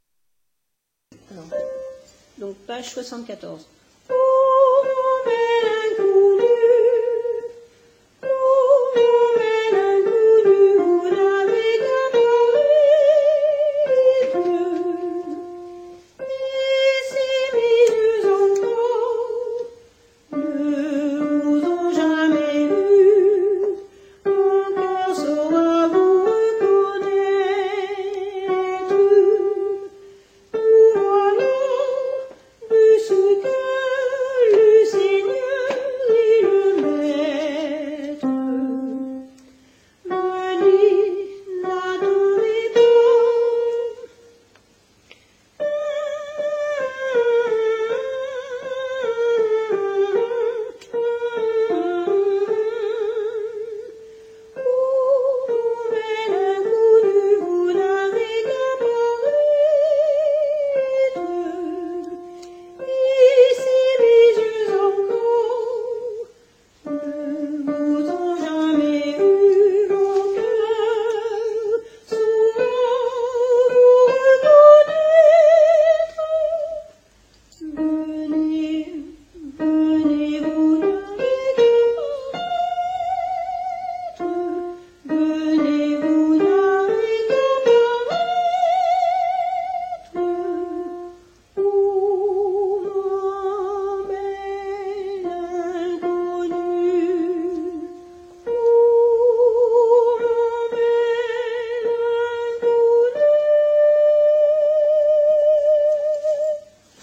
alti 1 pe 74 a la fin